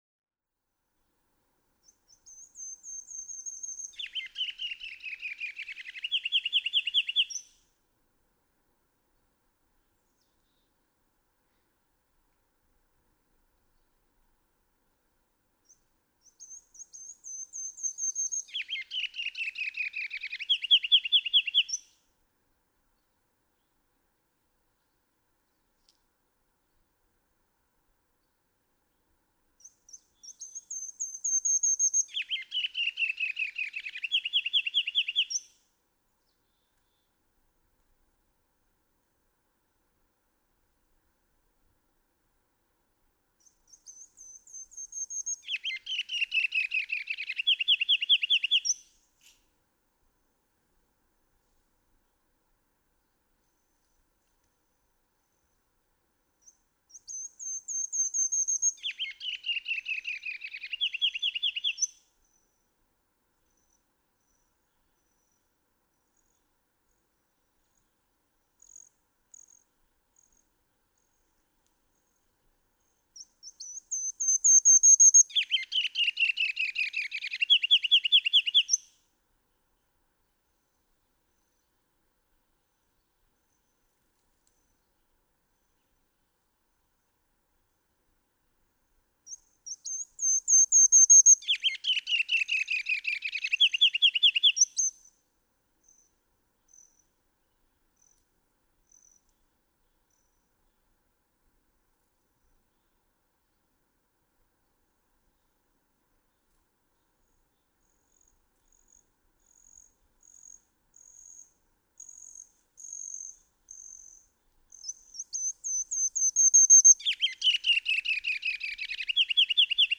Ruby-crowned kinglet
Each male may have only one song, but it's a masterpiece when complete: Tseee tseee tseee tsee tsee tse tse tew tew tew tew tew tew te te te tee-LETT tee-LETT tee-LETT tee-LETT tee-LETT.
St. Elmo, Colorado.
386_Ruby-crowned_Kinglet.mp3